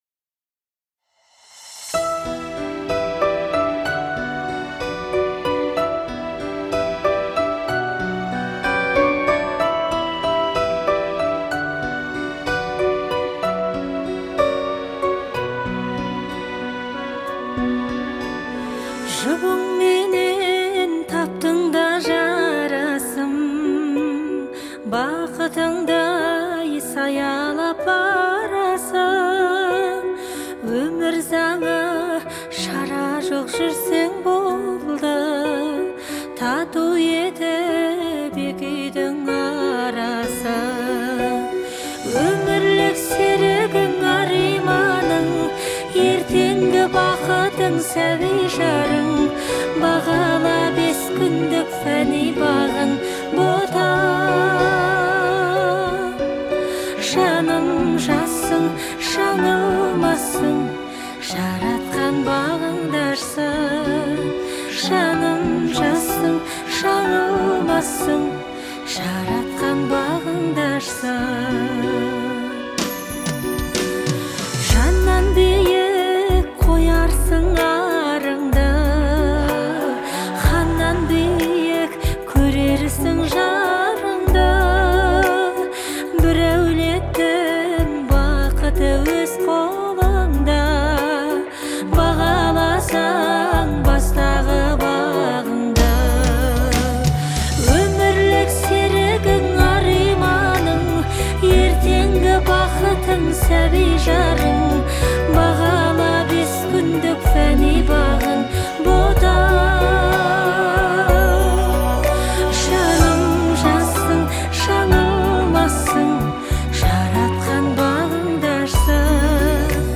мелодичный вокал